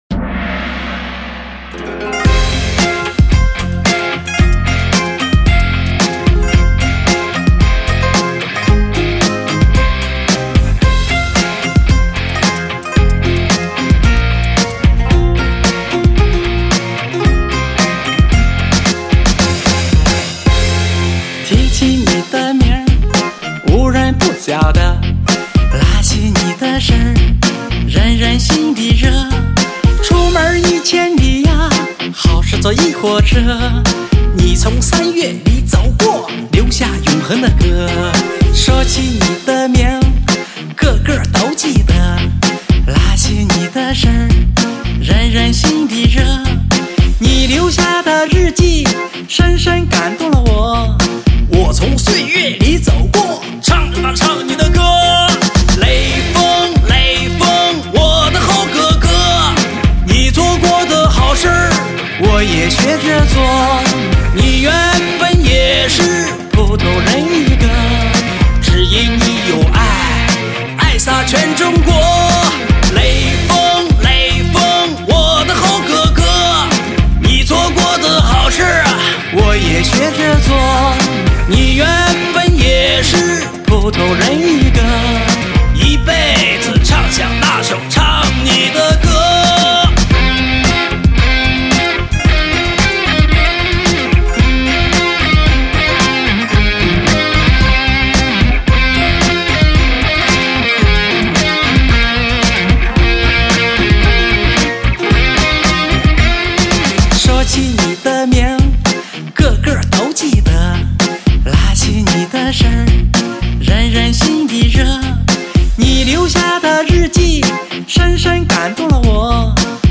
合唱